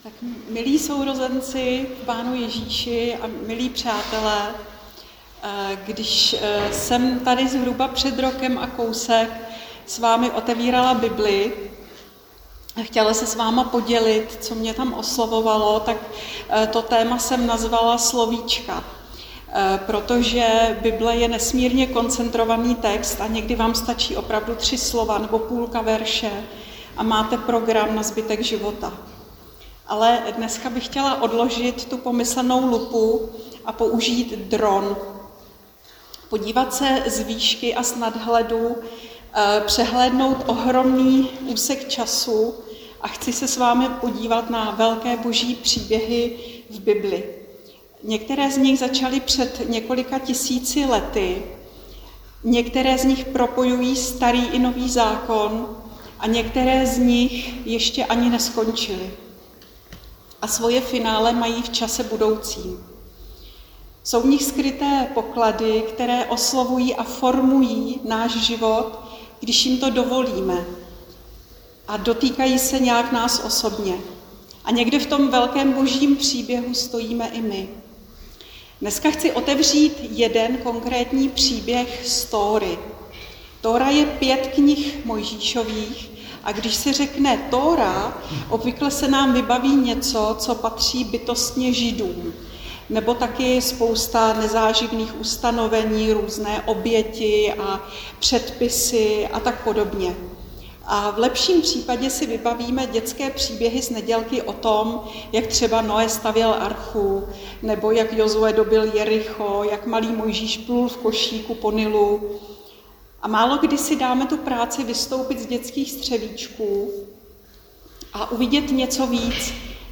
Křesťanské společenství Jičín - Kázání